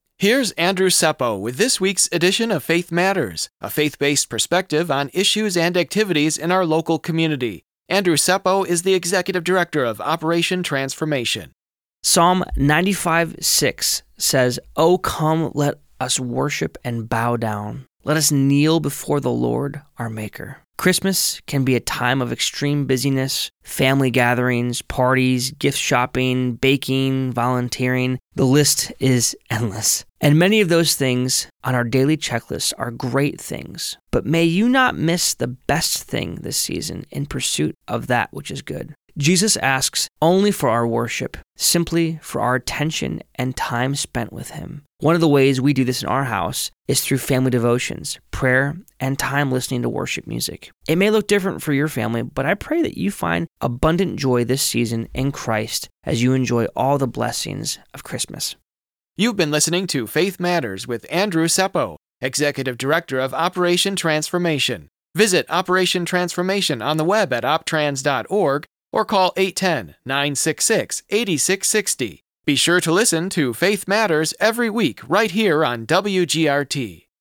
Faith Matters is a weekly radio feature that airs every Monday on WGRT 102.3 FM. Featuring information and commentary about spiritual issues - nationally and in your local community.